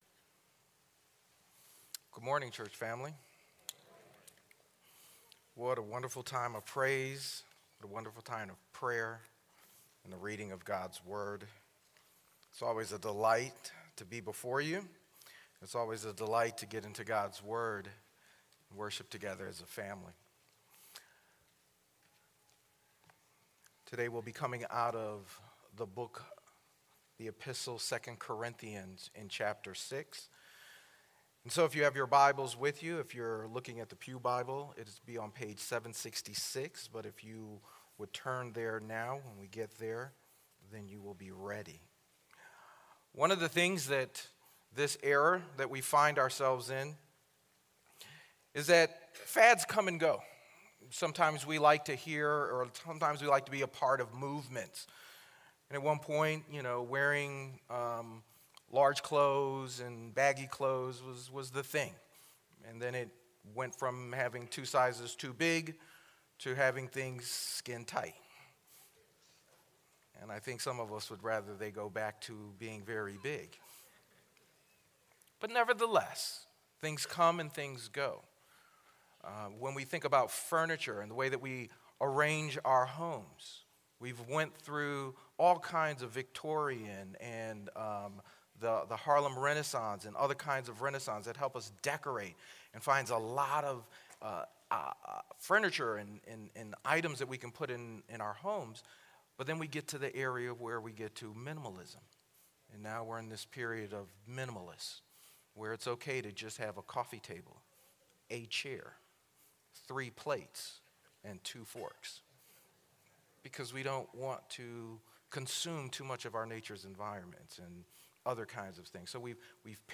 Sermon Outline 1.